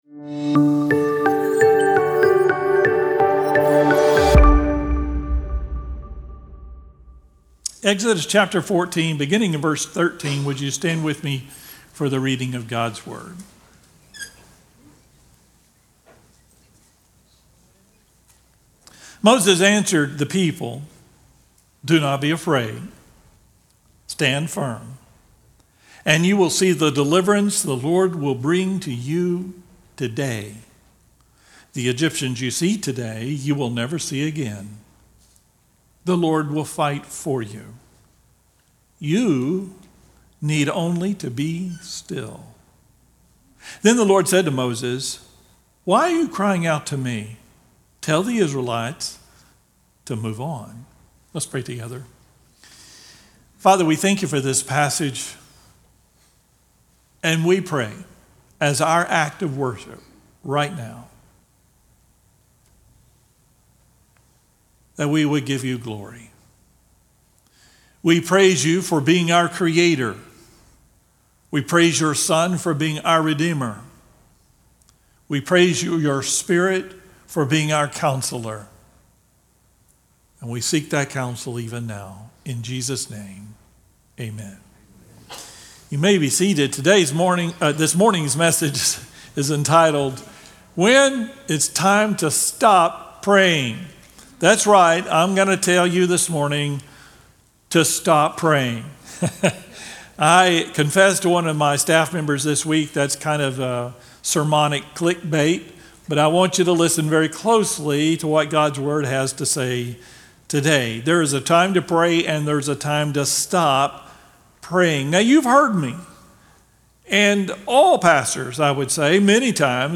2025-07-27-Sermon-2.mp3